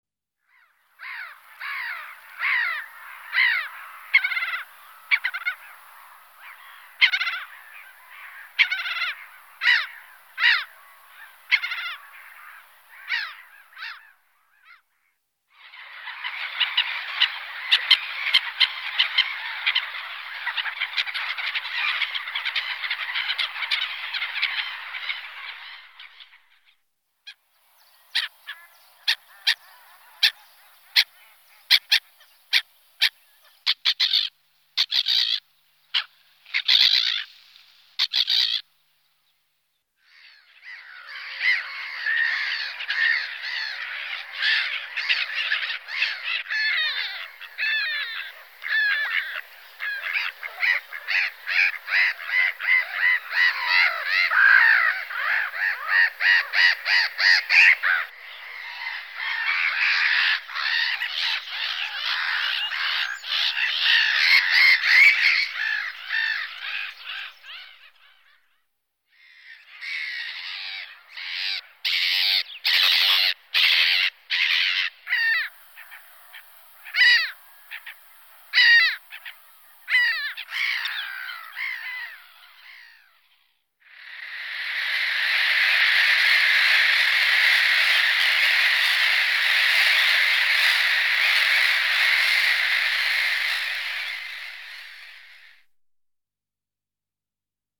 Голоса птахів
U Мартин звичайний
larus_ridibundus.MP3